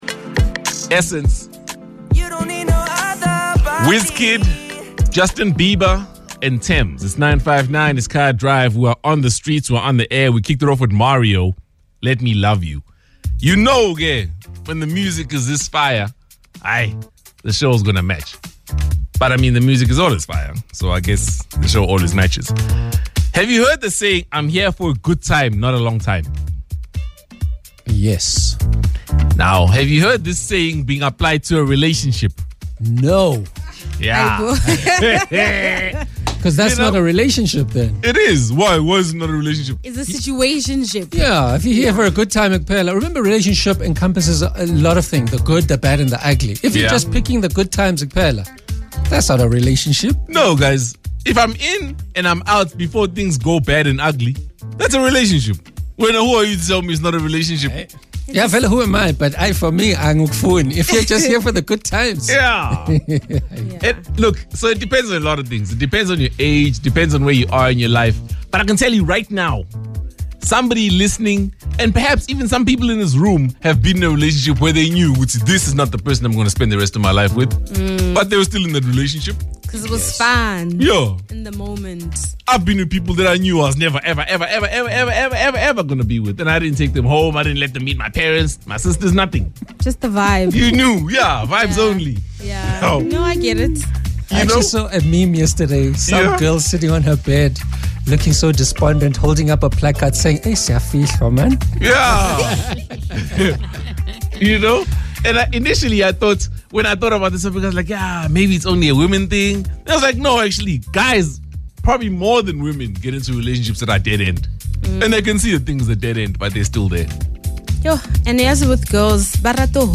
This Tuesday on Kaya Drive, Sizwe Dhlomo and his team broke down what it means to be in a dead-end relationship.